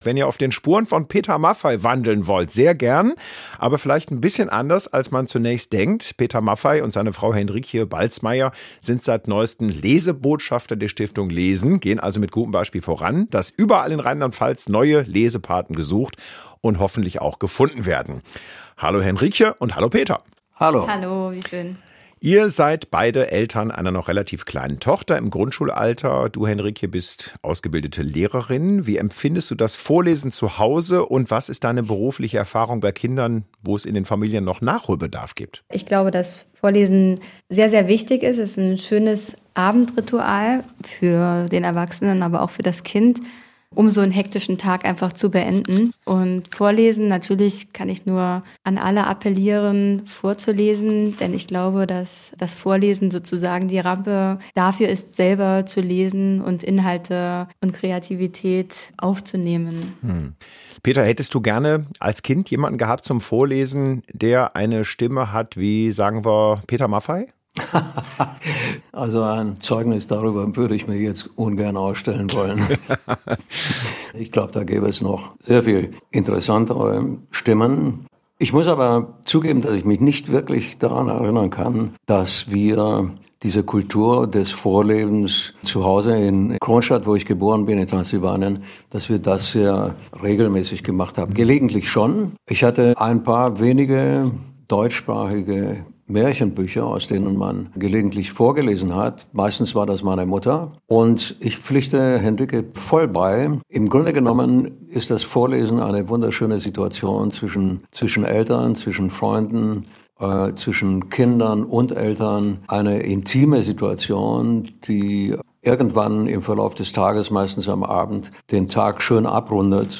Im Interview erzählen sie, warum das Vorlesen so wichtig ist und was ihre Tochter am liebsten vorgelesen bekommt.